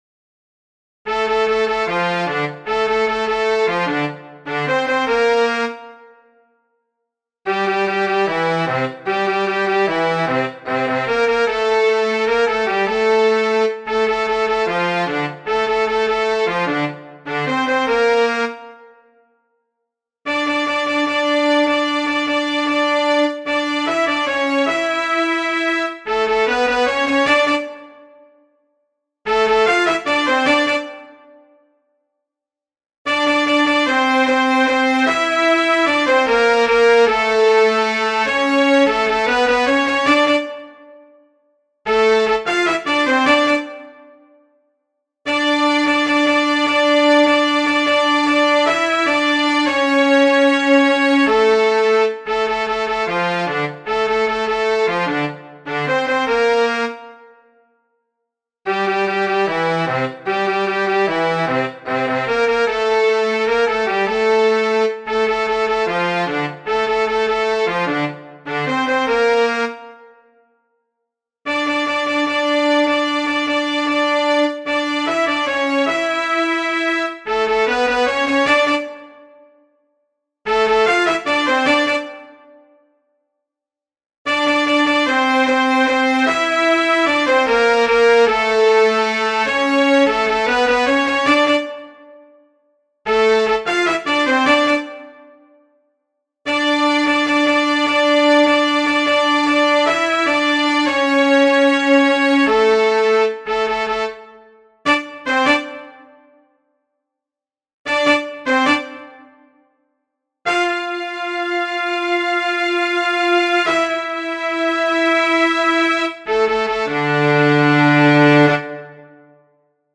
solo in C/Eb/Bb